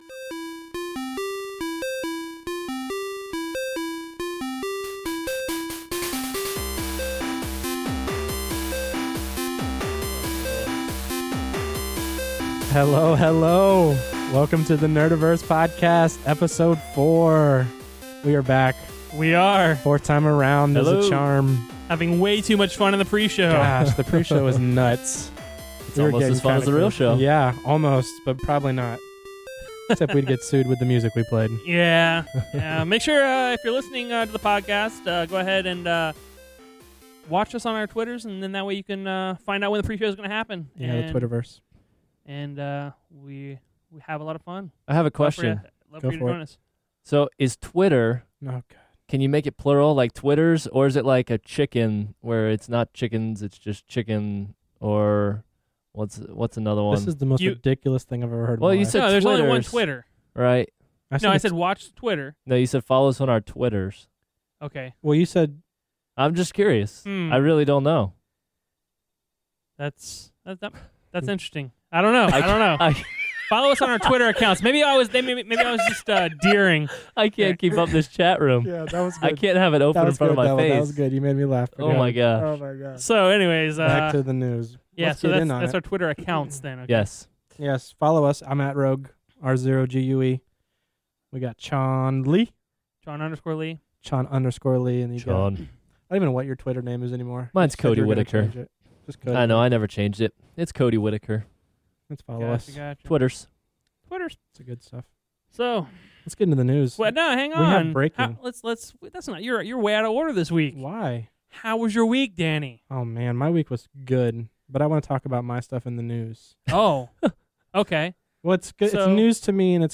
News, news and more news, we have our first random caller, and talk about more news.